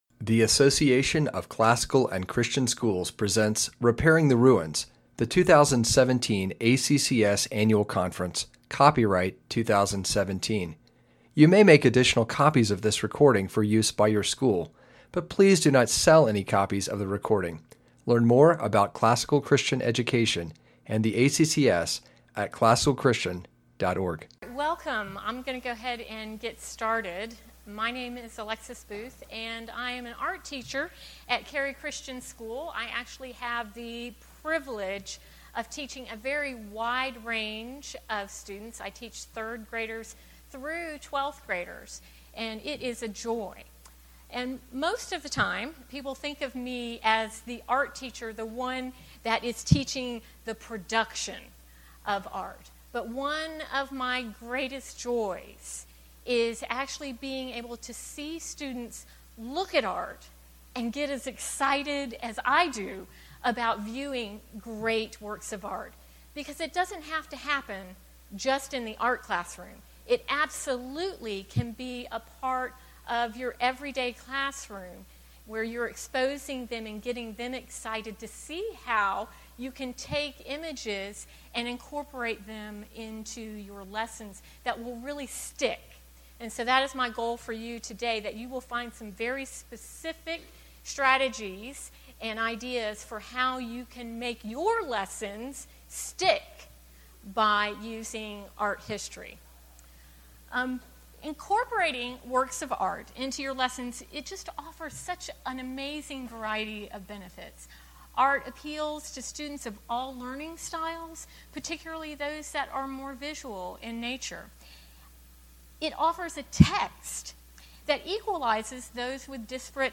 2017 Workshop Talk | 0:47:38 | Art & Music, General Classroom
In a culture that teaches that there is no standard in art, how can we integrate the biblical standard of art to reinforce the written record of history? In this lecture, learn practical strategies for making history “click” through masterwork art integration. Speaker Additional Materials The Association of Classical & Christian Schools presents Repairing the Ruins, the ACCS annual conference, copyright ACCS.